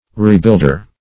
\Re*build"er\ (r[=e]*b[i^]ld"[~e]r)
rebuilder.mp3